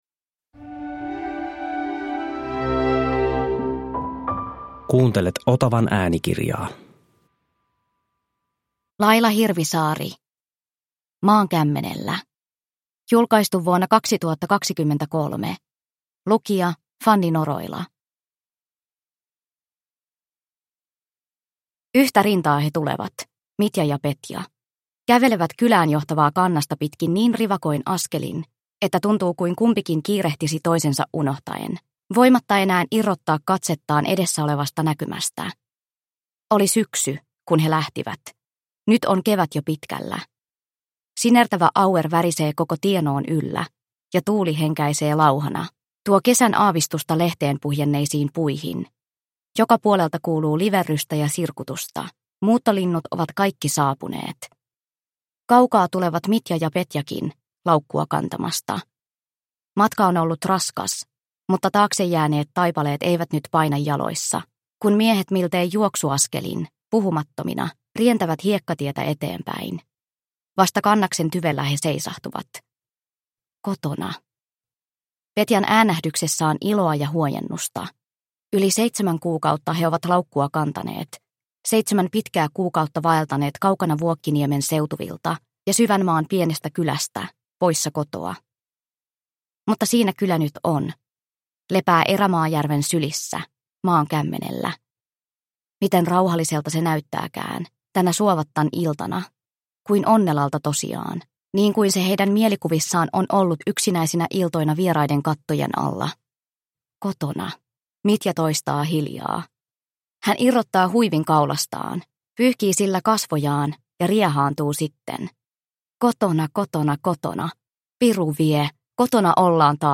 Maan kämmenellä (ljudbok) av Laila Hirvisaari